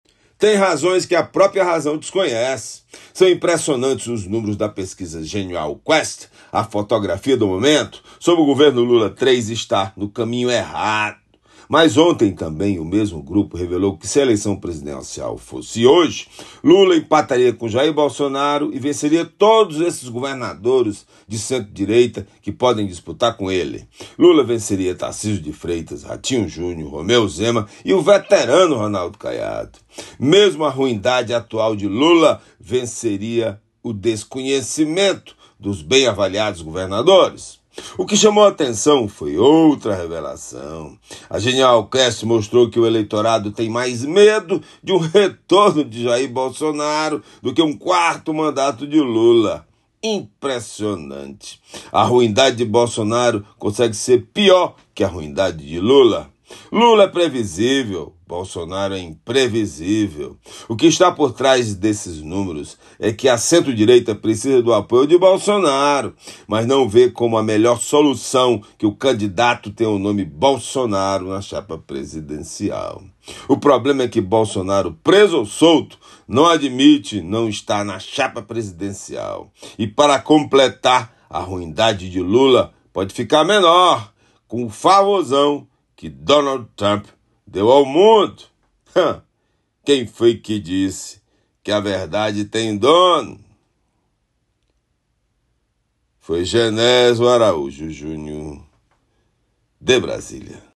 COMENTARIO-04-04.mp3